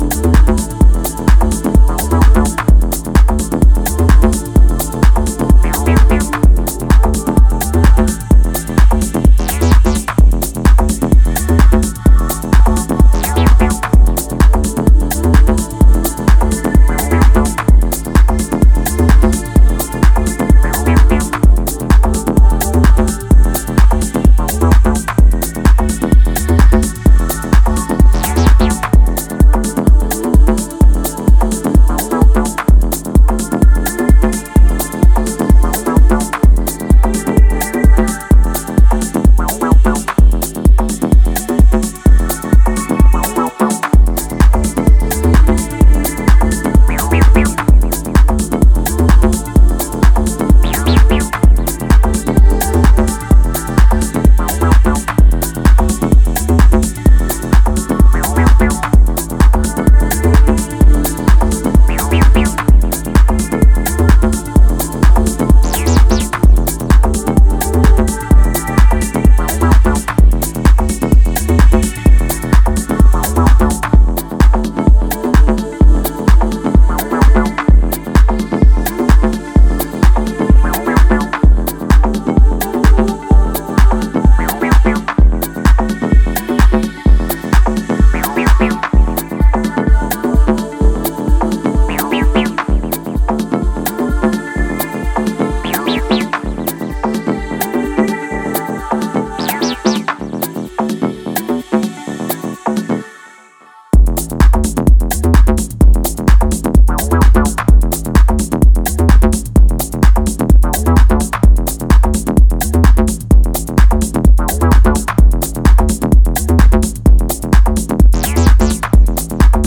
one of the most creative Techno producers in recent years